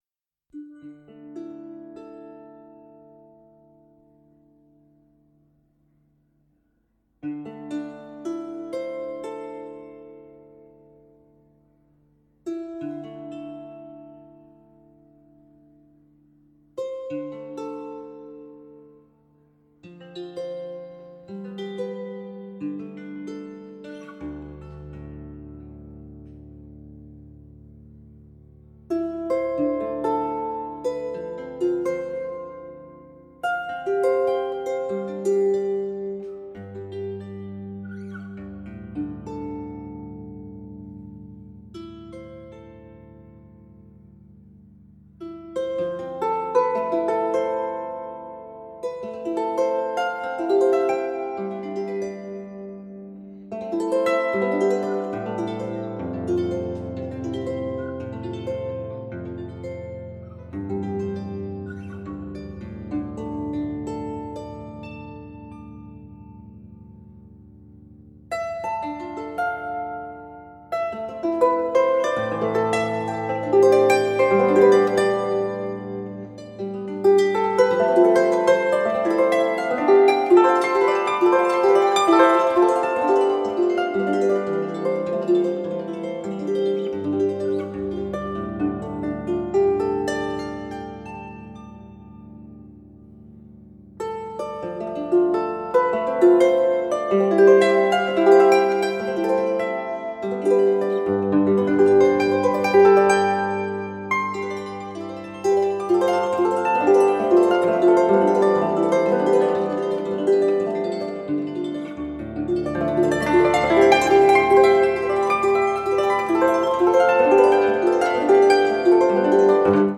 深遠で可憐なハープの音色に心地良く酔える傑作です！